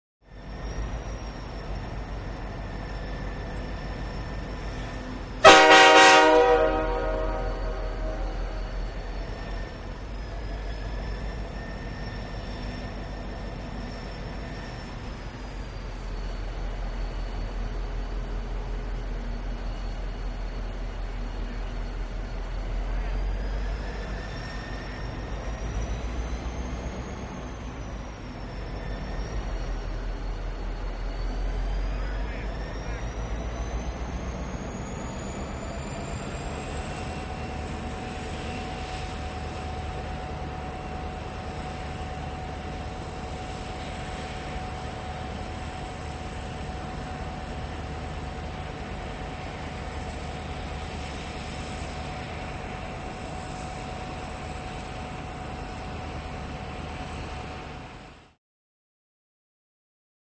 Train, Exterior Perspective; Idle Medium Close. Horn Blow Close At Head.